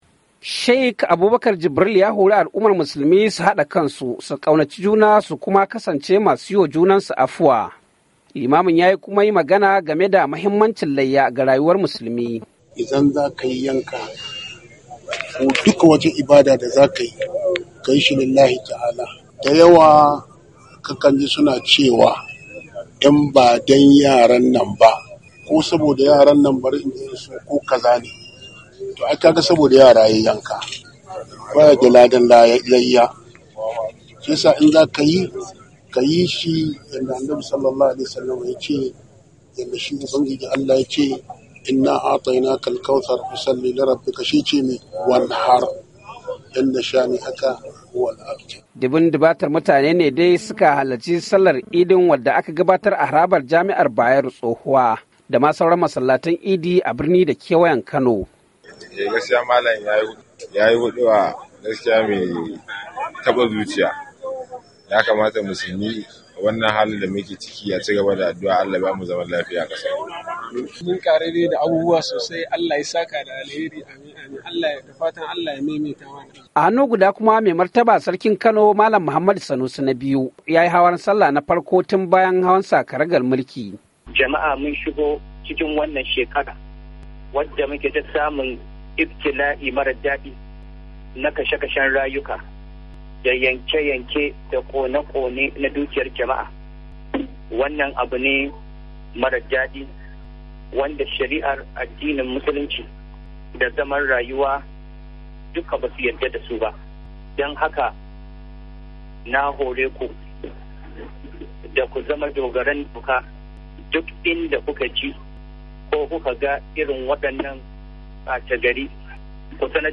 Sallar Idi a Kano